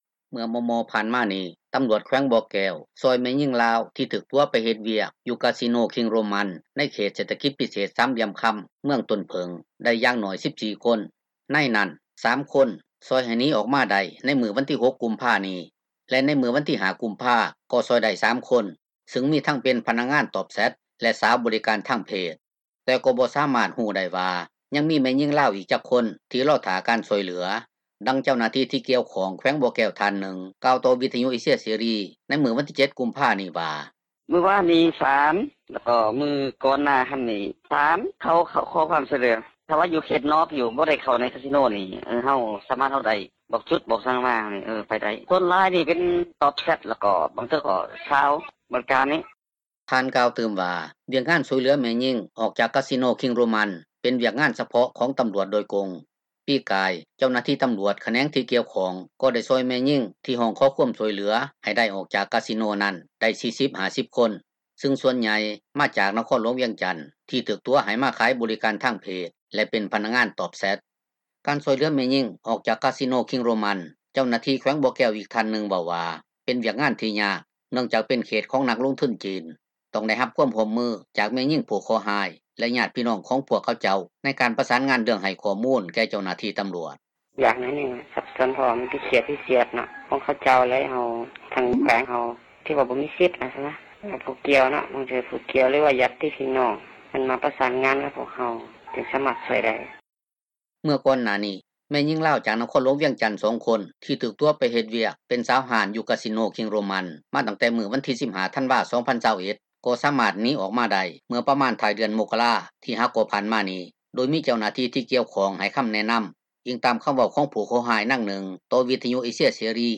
ດັ່ງເຈົ້າໜ້າທີ່ ທີ່ກ່ຽວຂ້ອງ ແຂວງບໍ່ແກ້ວທ່ານນຶ່ງ ກ່າວຕໍ່ ວິທຍຸເອເຊັຽເສຣີໃນມື້ວັນທີ 7 ກຸມພານີ້ວ່າ: